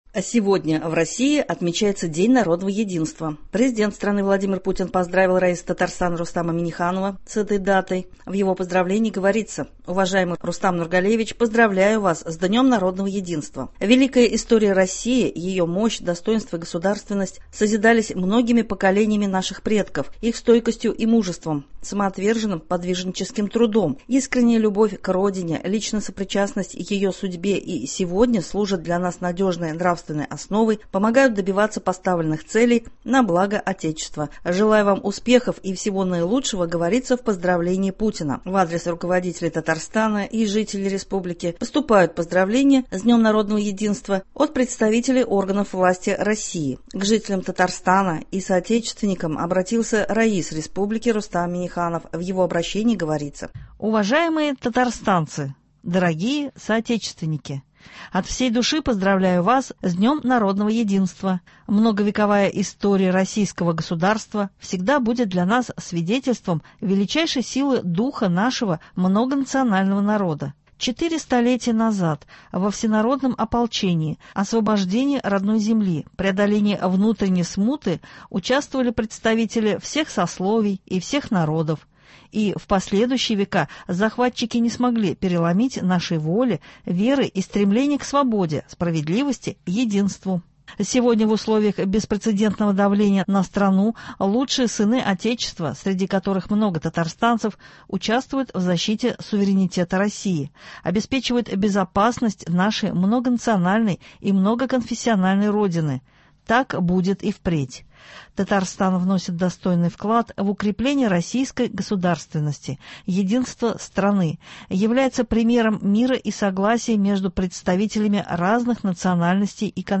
Спецвыпуск новостей.